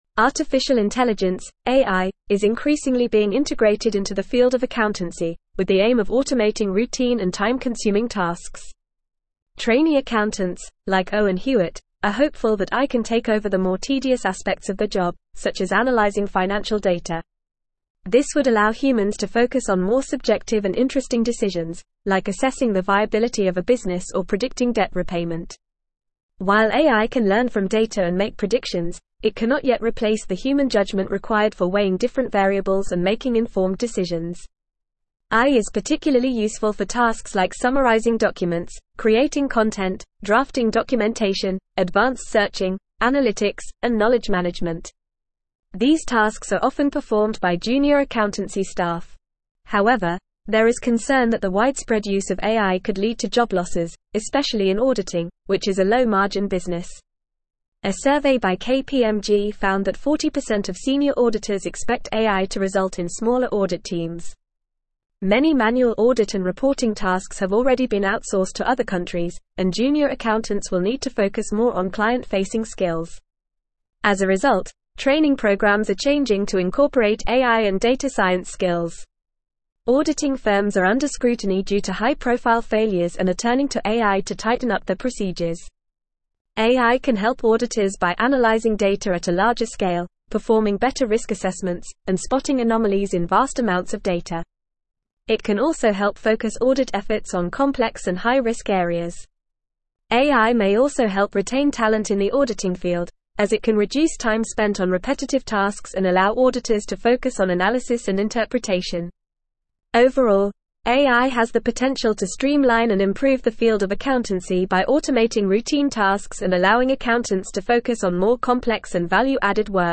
Fast
English-Newsroom-Advanced-FAST-Reading-AI-Revolutionizing-Accountancy-Streamlining-Processes-and-Improving-Audits.mp3